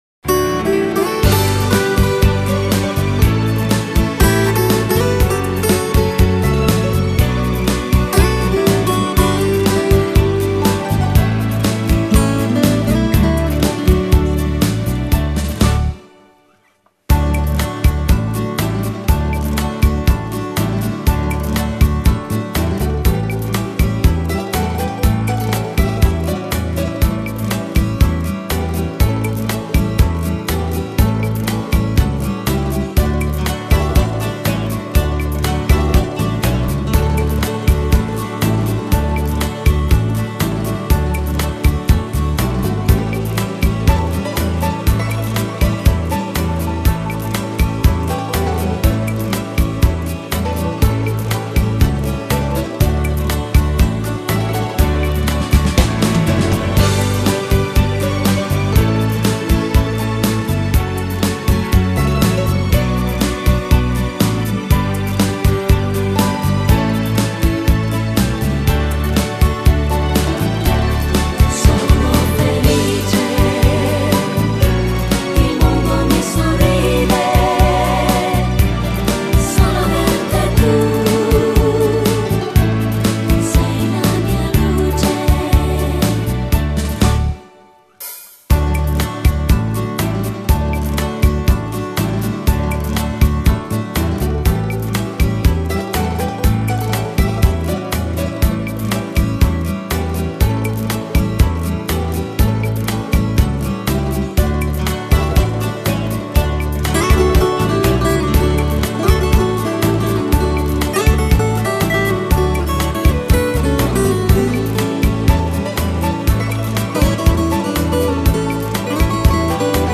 Genere: Beguine
Scarica la Base Mp3 (2,90 MB)